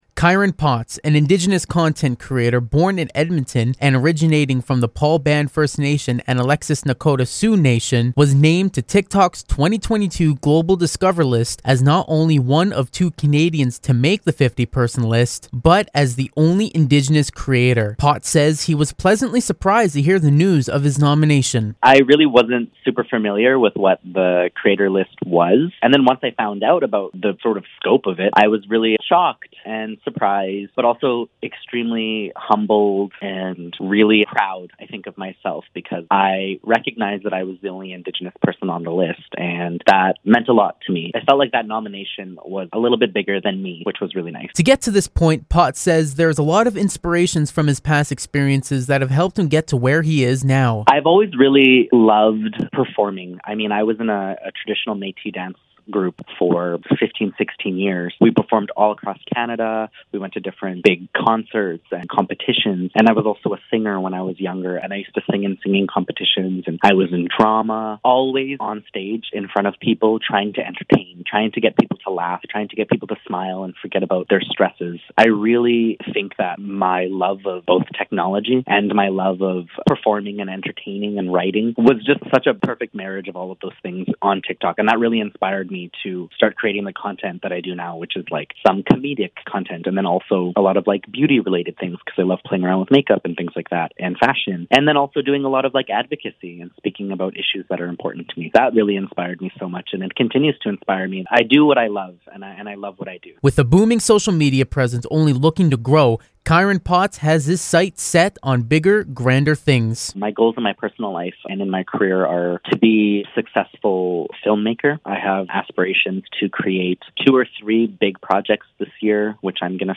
Listen to the full CFWE Interview